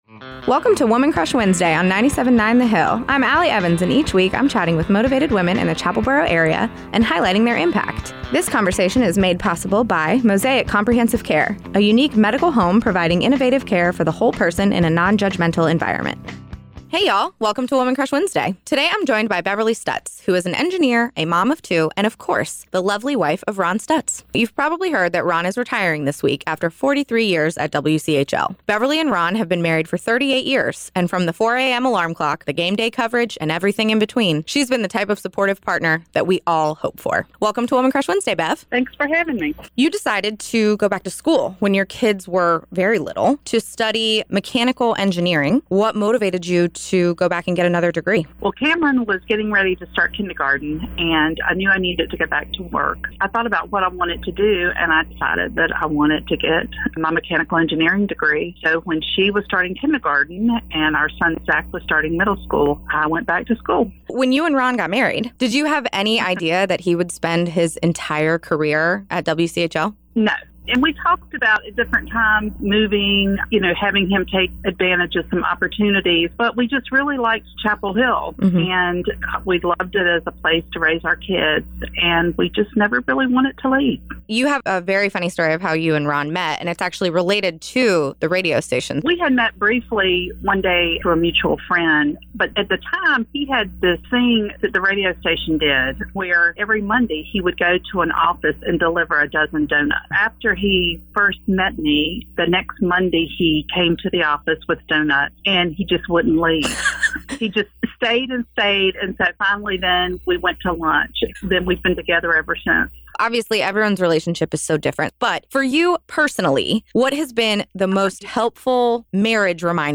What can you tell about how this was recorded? ” a three-minute weekly recurring segment made possible by Mosaic Comprehensive Care that highlights motivated women and their impact both in our community and beyond.